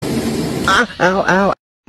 Goofy Ahh Weird Noises Meme Grunts Sound Effect Download: Instant Soundboard Button